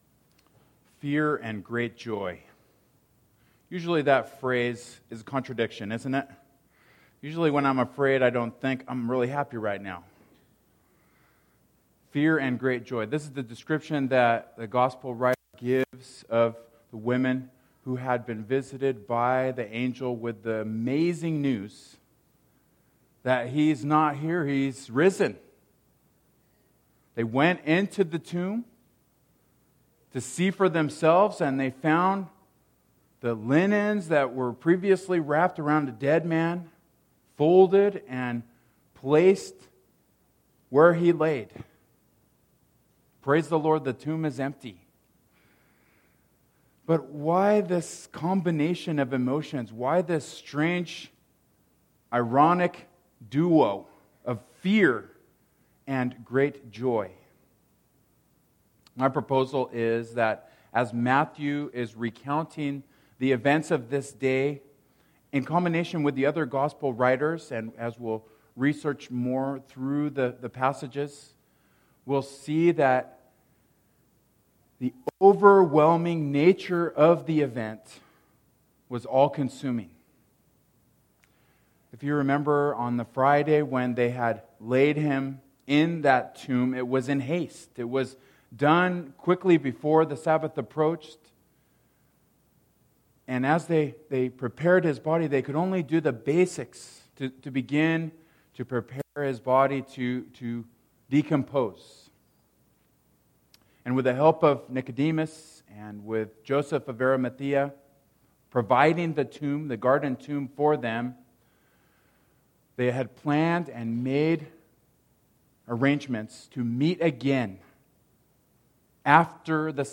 Matthew 28:8 Service Type: Worship Service « Do You Love Me?